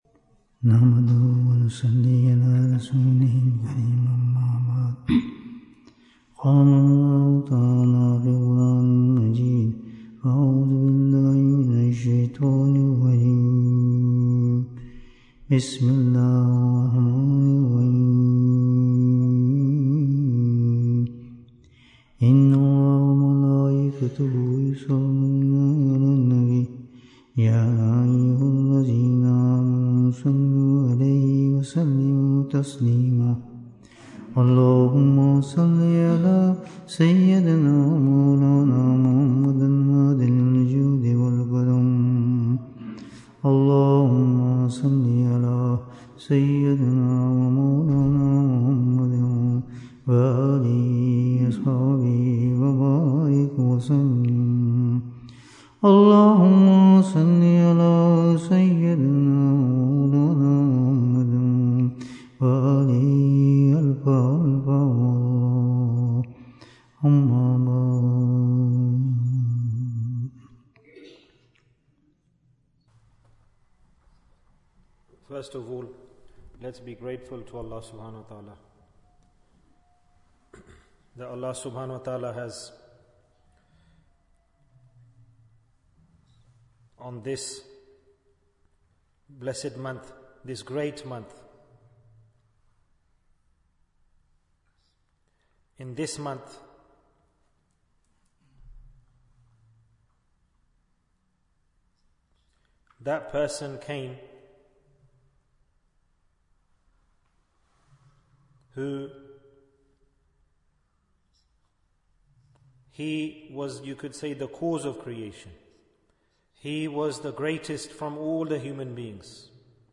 The Main Reason for Going to Jahannum Bayan, 88 minutes26th September, 2024